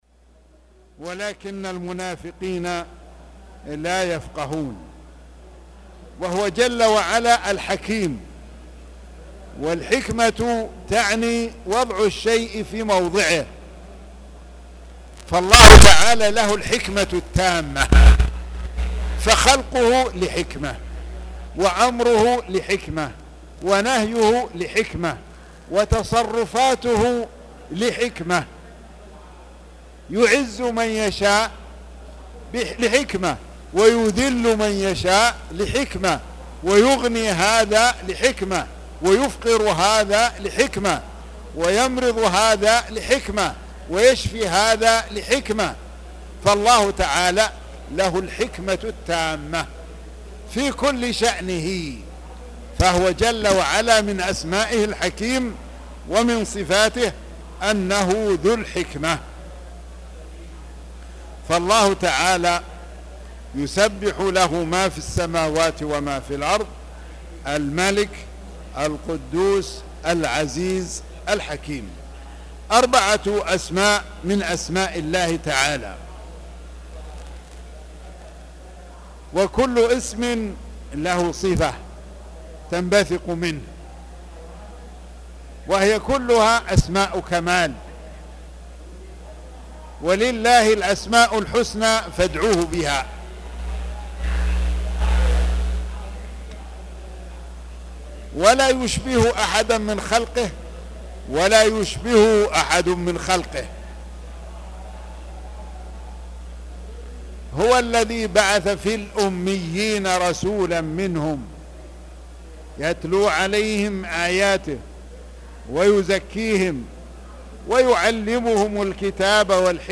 تاريخ النشر ٨ جمادى الأولى ١٤٣٨ هـ المكان: المسجد الحرام الشيخ